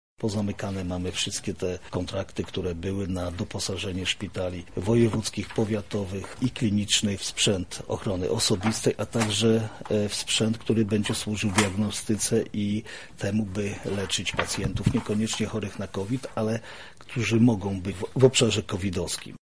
To jest dopiero pierwszy etap walki z wirusem – mówi marszałek województwa lubelskiego Jarosław Stawiarski: